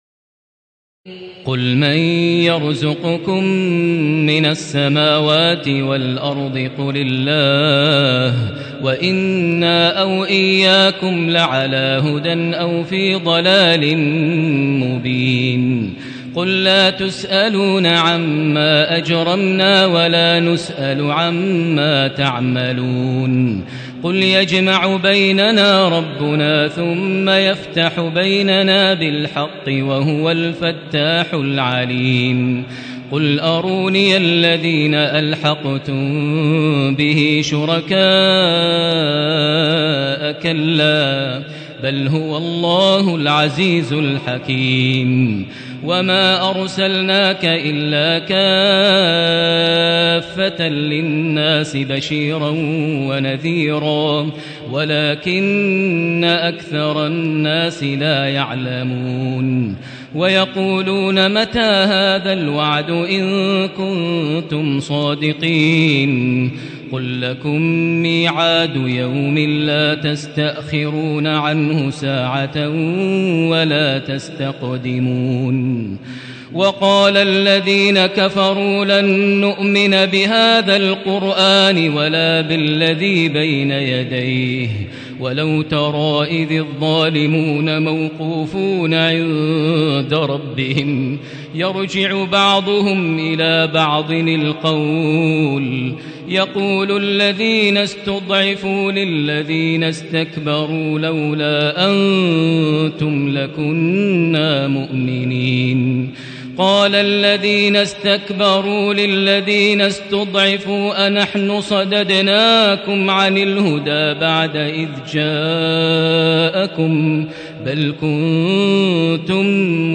تراويح ليلة 21 رمضان 1437هـ من سور سبأ (24-54) وفاطر و يس(1-32) Taraweeh 21 st night Ramadan 1437H from Surah Saba and Faatir and Yaseen > تراويح الحرم المكي عام 1437 🕋 > التراويح - تلاوات الحرمين